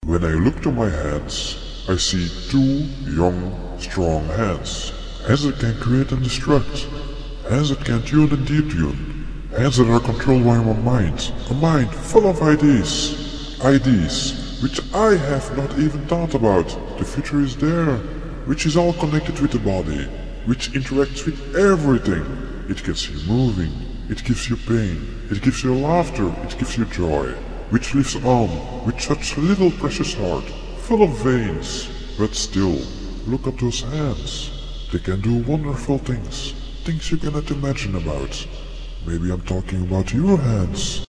Tag: 清唱 DJ DJ-工具 口语 声乐